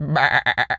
pgs/Assets/Audio/Animal_Impersonations/sheep_baa_bleat_04.wav at 7452e70b8c5ad2f7daae623e1a952eb18c9caab4
sheep_baa_bleat_04.wav